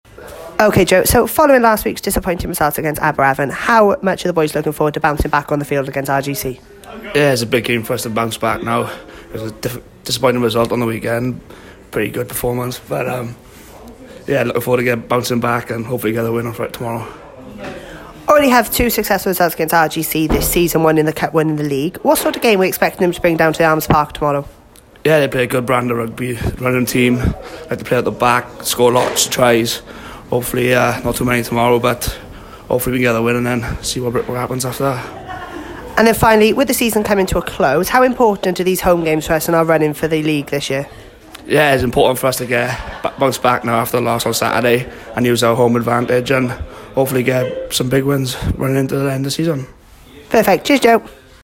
Pre Match Interview.